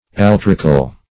altrical - definition of altrical - synonyms, pronunciation, spelling from Free Dictionary Search Result for " altrical" : The Collaborative International Dictionary of English v.0.48: Altrical \Al"tri*cal\, a. (Zool.)
altrical.mp3